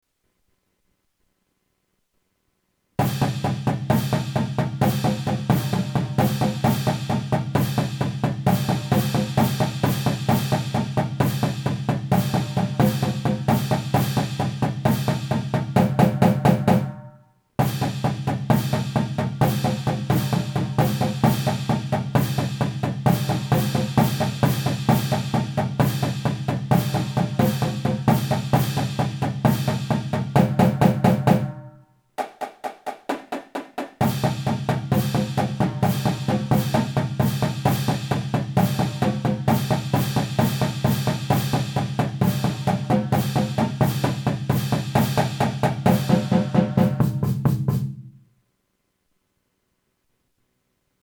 The Crystal Lake Strikers Allstars are a percussion unit made up of drummers from high schools around Mchenry County.